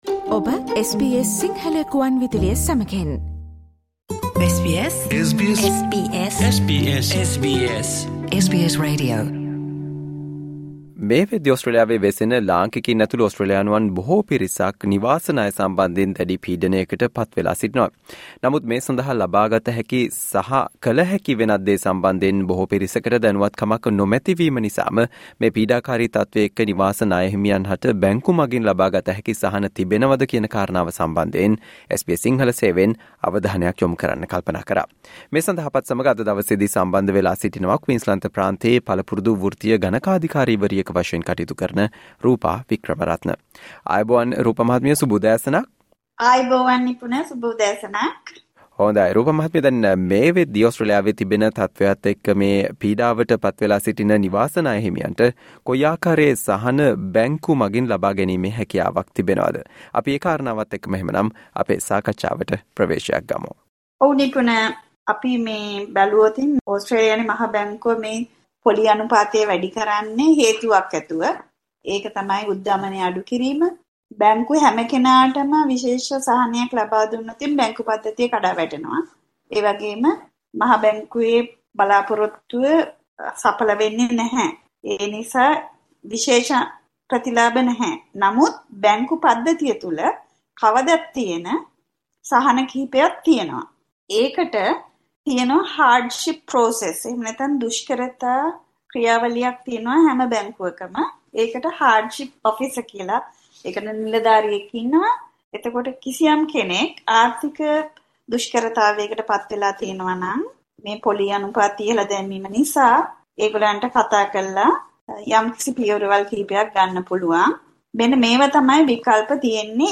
SBS Sinhala Interview